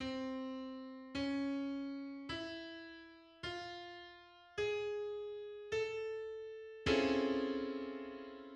"Ode-to-Napoleon" hexachord[1] in prime form[2]
It is also Ernő Lendvai's "1:3 Model" scale and one of Milton Babbitt's six all-combinatorial hexachord "source sets".[2]
'Ode-to-Napoleon'_hexachord.mid.mp3